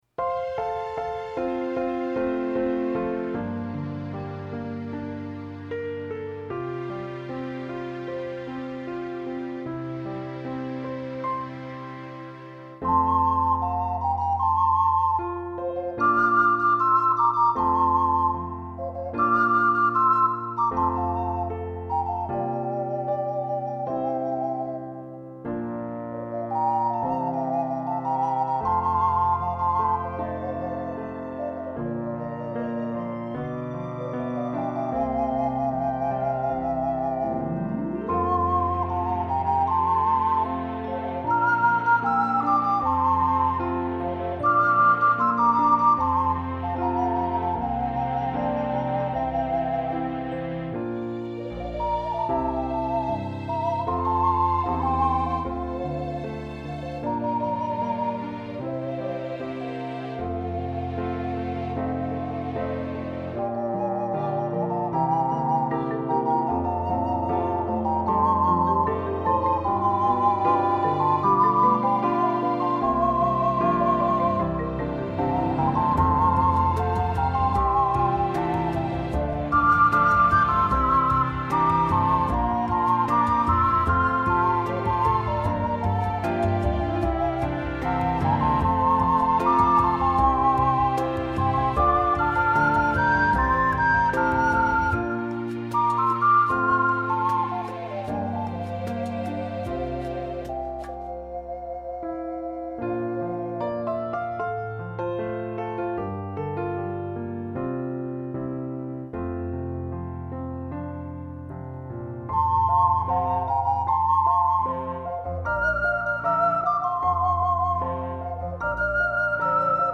＊試奏は雑に録音・編集したので、参考程度と思ってください。